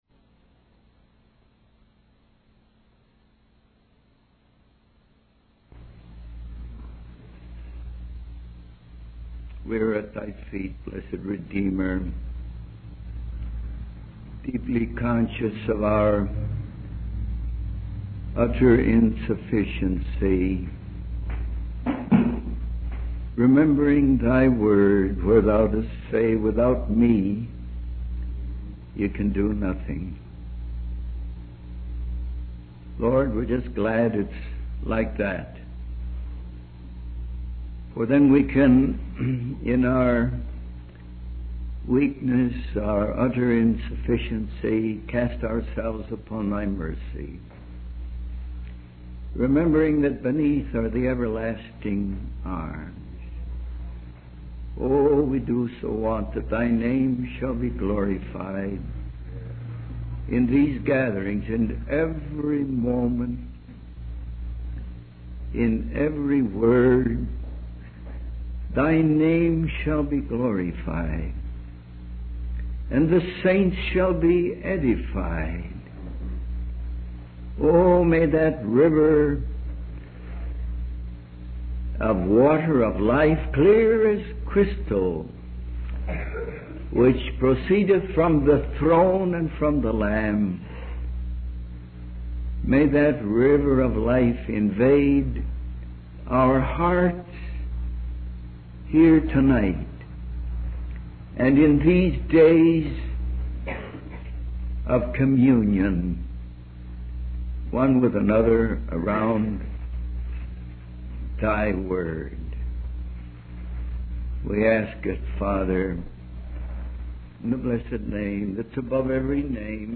In this sermon, the preacher focuses on the symbolism of the union between the heavenly bridegroom (Jesus) and the bride (the church). The miracle of turning water into wine at the wedding in Cana sets the tone for the series of miracles performed by Jesus during his time on earth.